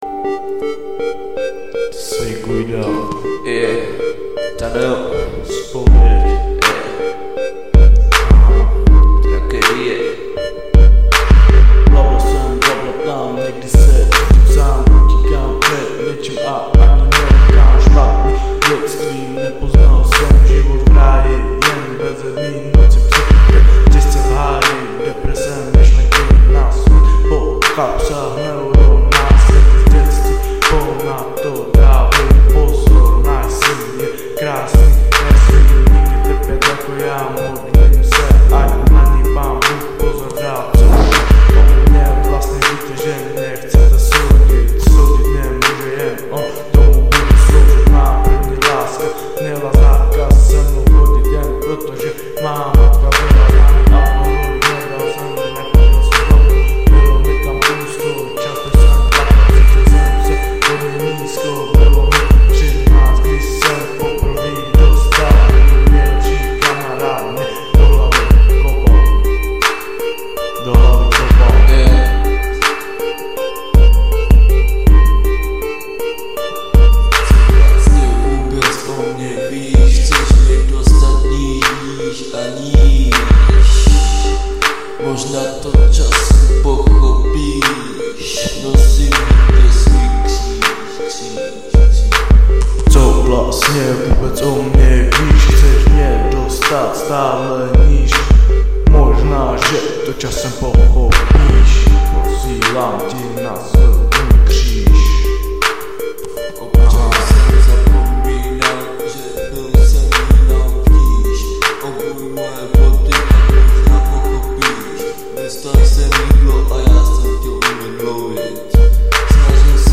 Žánr: Hip Hop/R&B